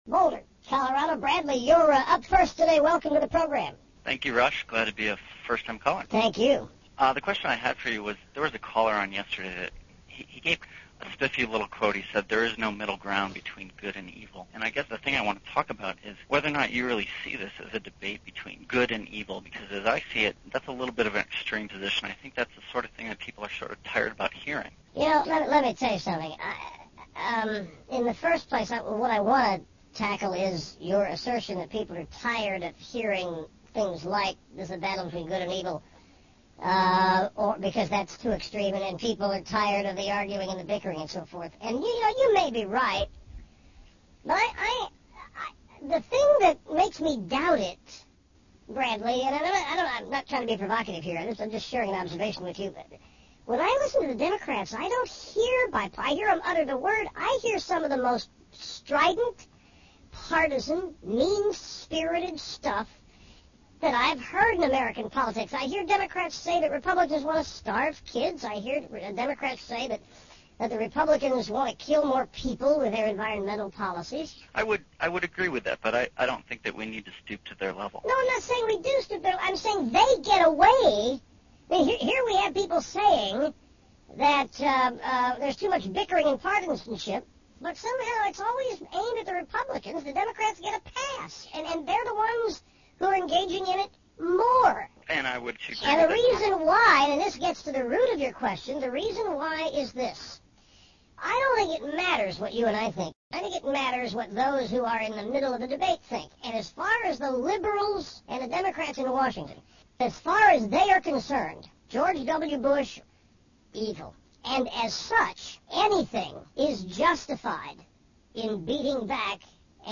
Rush Limbaugh as a chipmunk!